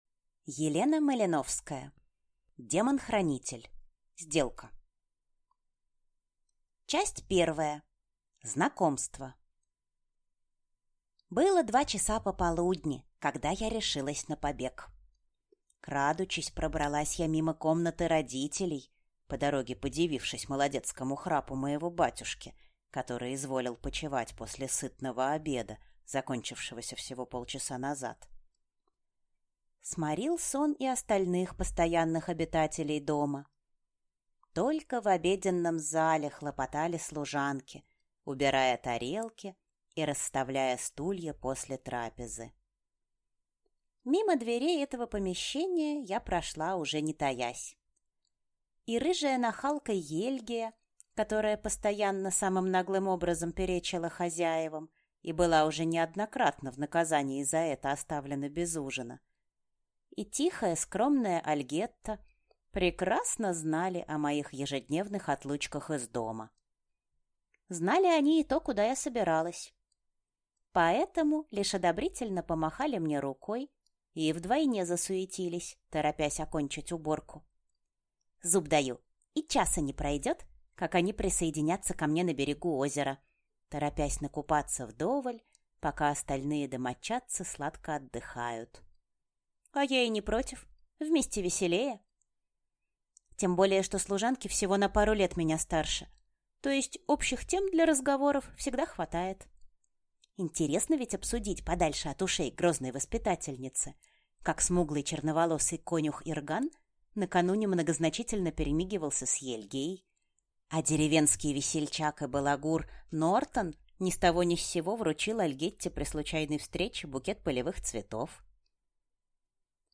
Аудиокнига Демон-хранитель. Сделка | Библиотека аудиокниг